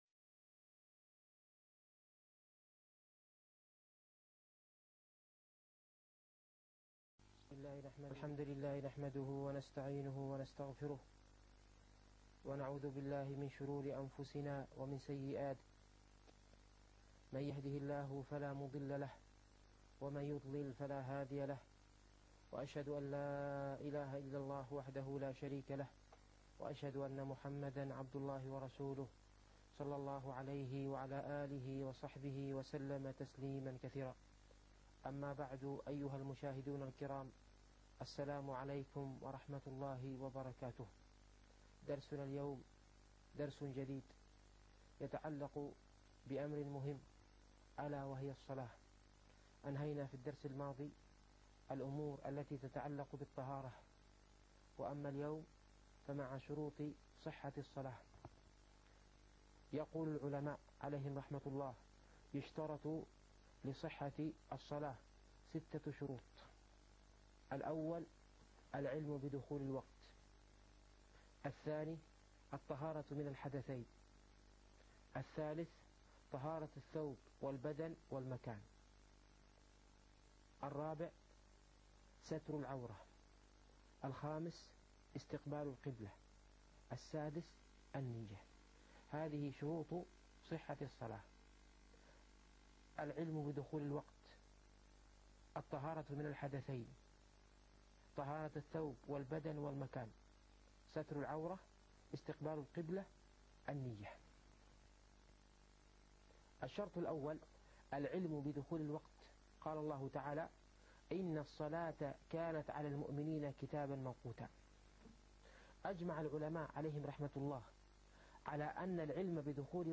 الفقه الميسر - الدرس الثالث عشر